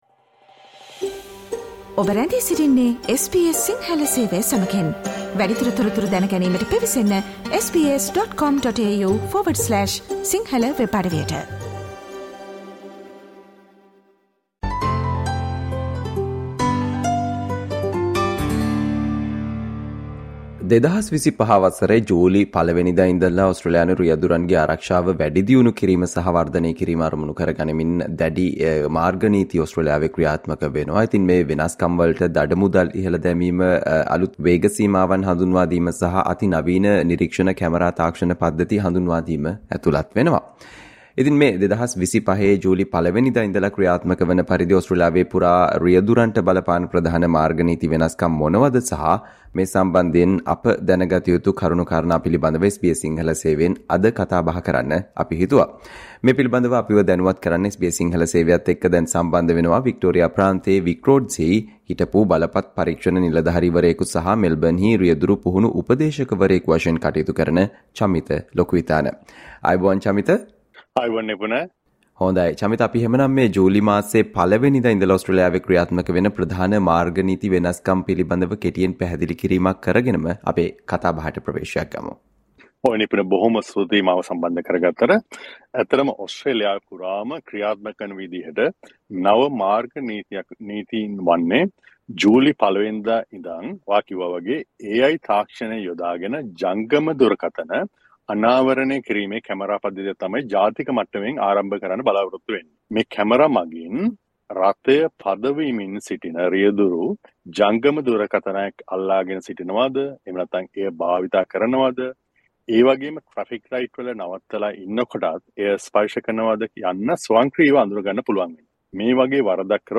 2025 ජූලි 1 වන දා සිට ක්‍රියාත්මක වන පරිදි ඕස්ට්‍රේලියාව පුරා රියදුරන්ට බලපාන ප්‍රධාන මාර්ග නීති වෙනස්කම් සහ ඒ සම්බන්ධයෙන් අප දැනගත යුතු කරුණු පිළිබඳව SBS සිංහල සේවය සිදු කල සාකච්චාවට සවන්දෙන්න